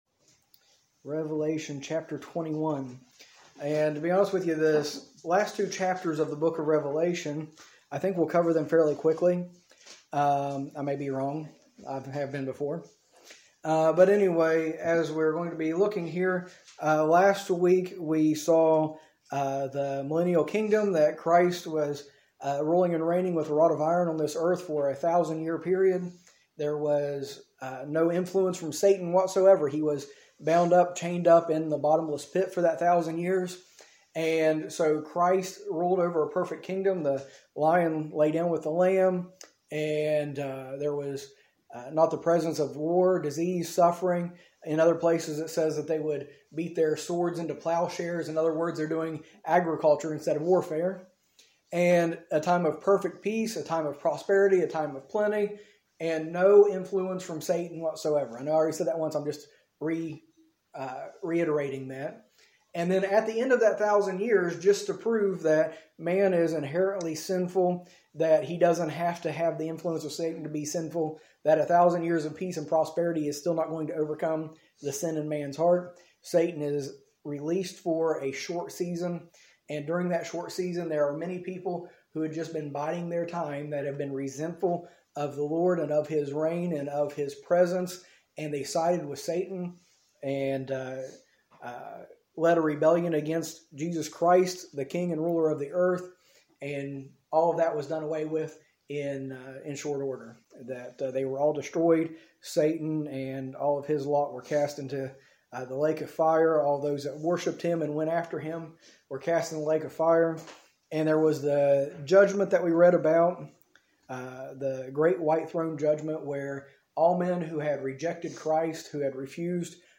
A message from the series "Study on the End Times."